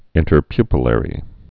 (ĭntər-pypə-lĕrē)